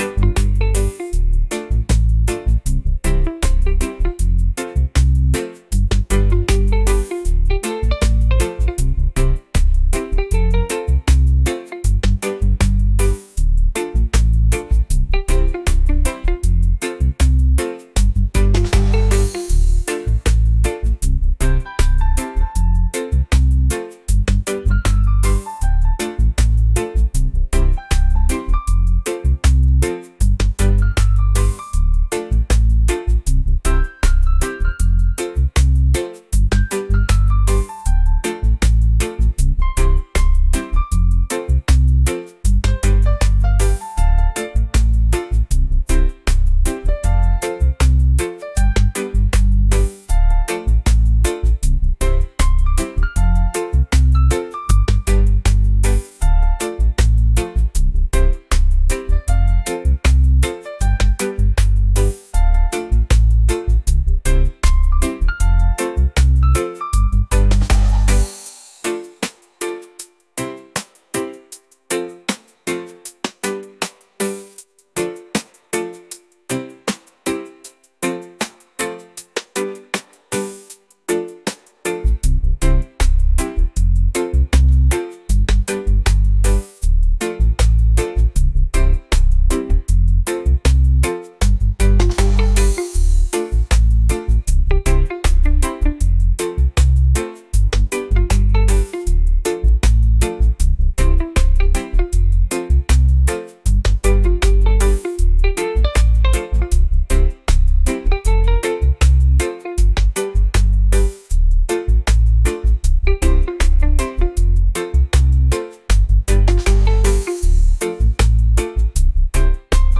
laid-back | island | vibes | reggae